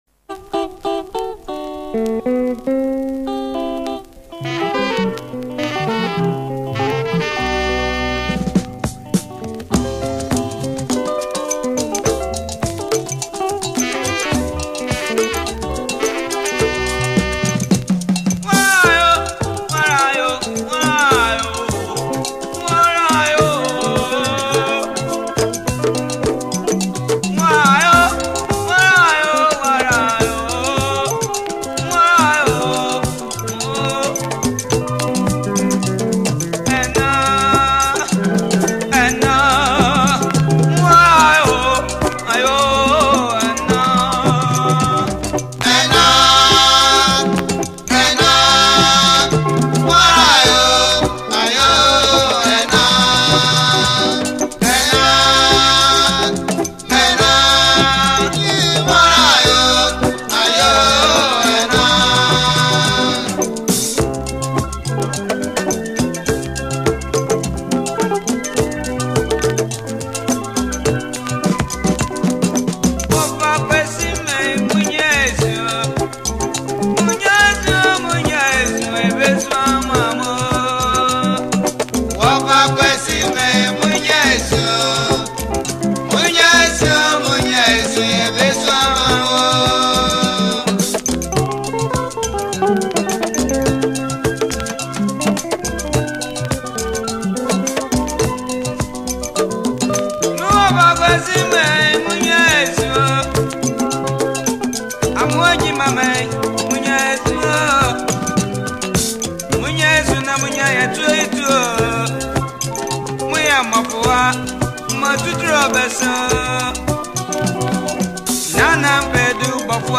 Ghana Highlife song